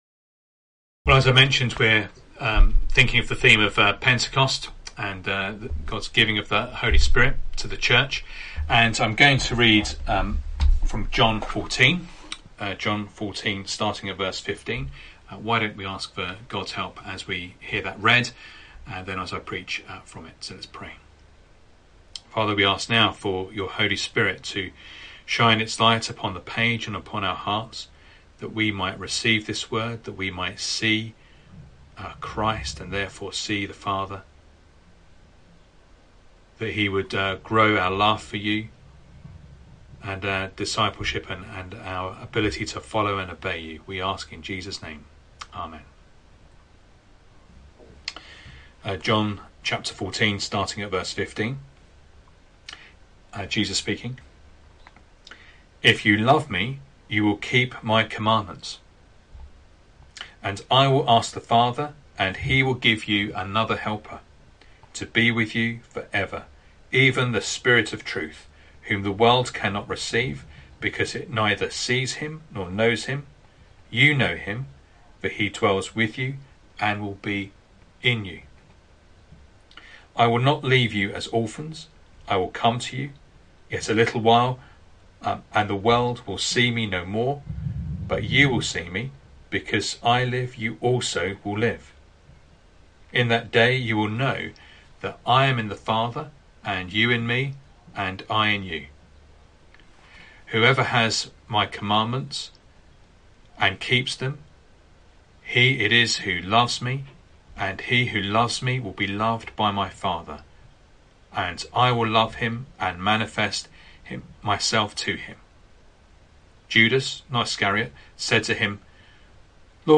Sunday Morning Reading and Sermon Audio